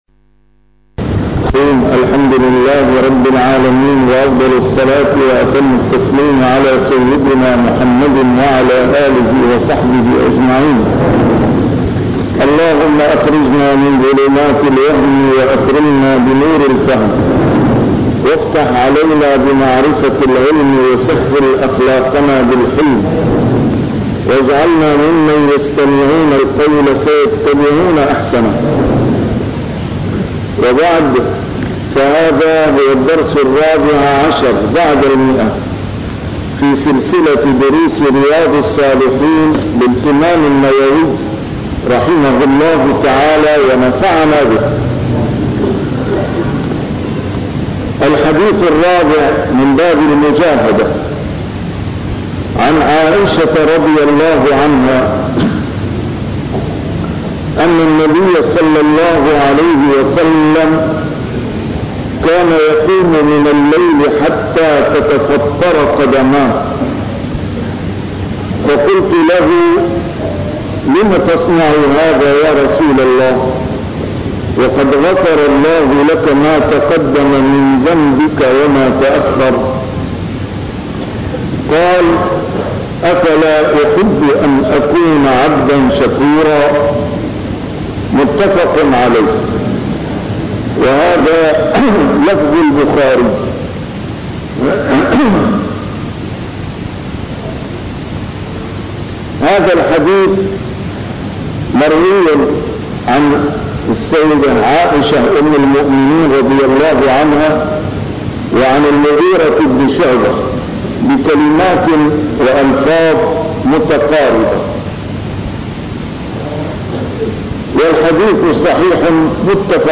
A MARTYR SCHOLAR: IMAM MUHAMMAD SAEED RAMADAN AL-BOUTI - الدروس العلمية - شرح كتاب رياض الصالحين - 114- شرح رياض الصالحين: المجاهدة